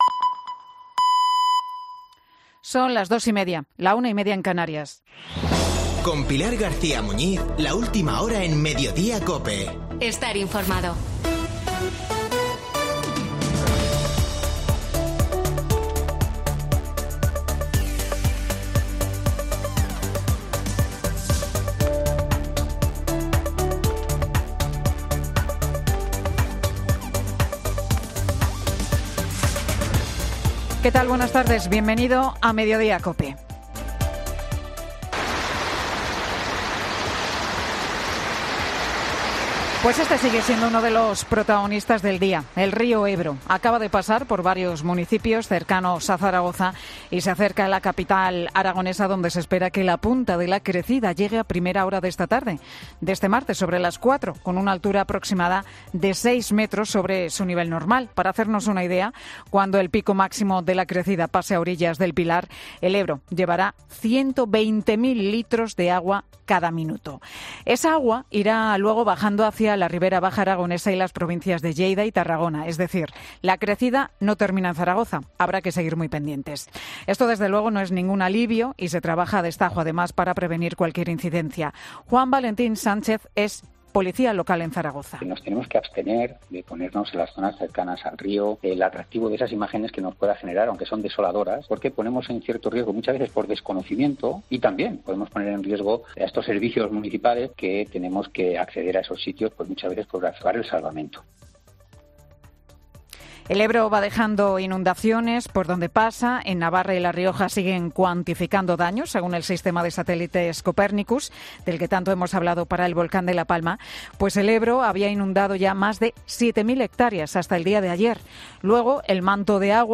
AUDIO: El monólogo de Pilar García Muñiz, en Mediodía COPE